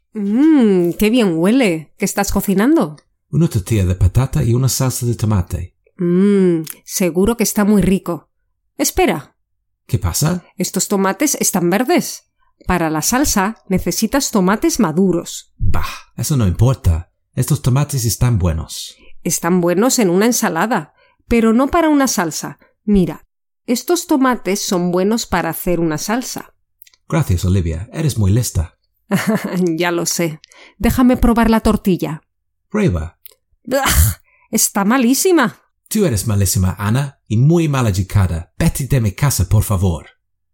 16.-Listening-Practice-Adjectives-with-Ser-Estar-Part-1.mp3